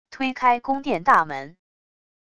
推开宫殿大门wav音频